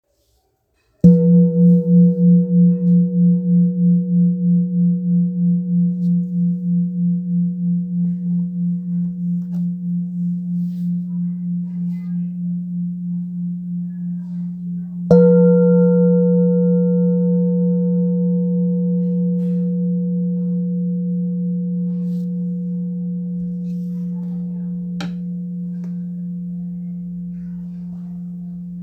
Kopre Singing Bowl, Buddhist Hand Beaten, Antique Finishing, Select Accessories
Material Seven Bronze Metal
It is accessible both in high tone and low tone .
In any case, it is likewise famous for enduring sounds. Kopre Antique Singing Bowls is accessible in seven different chakras tone.